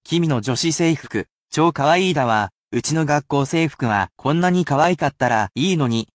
Each sentence is then read to you as many times as you wish, but it will be at full speed, so it is more useful to be used to pick the word out of the sentence rather than repeating.